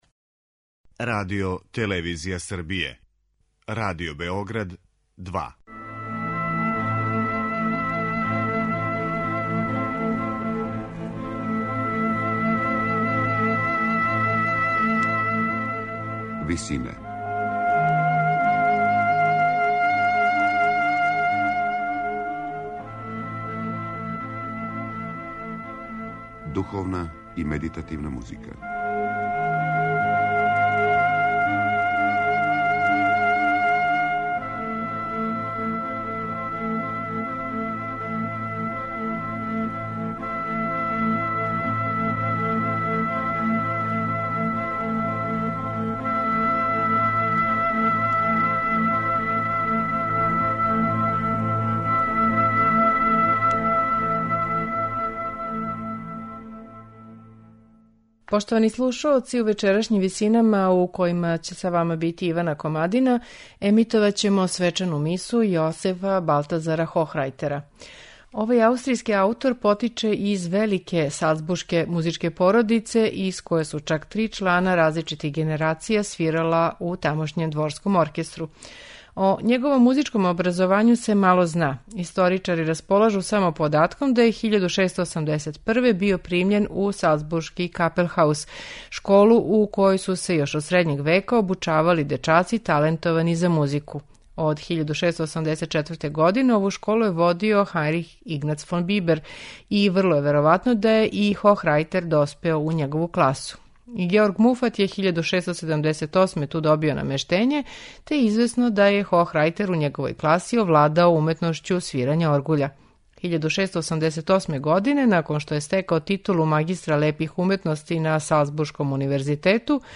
сопран
контратенор
тенор
бас), Дечји хор